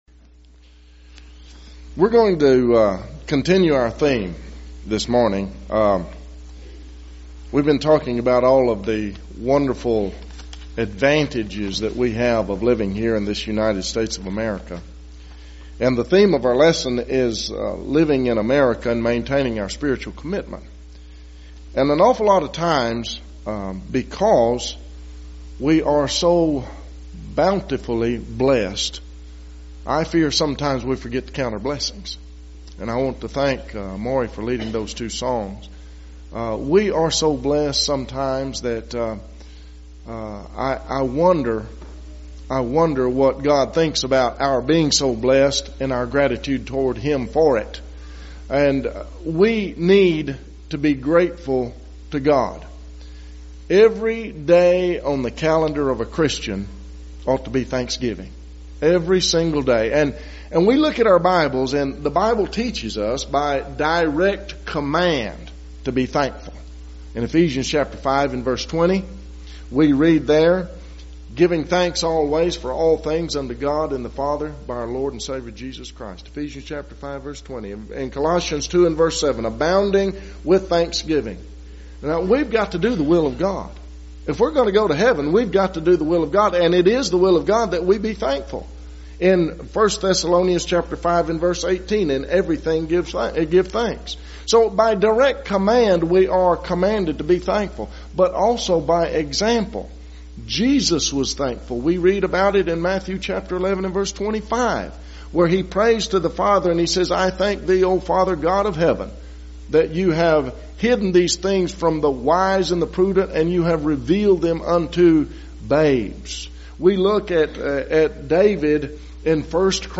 Series: Bangs, TX Gospel Meetings
Theme/Title: Spring Gospel Meeting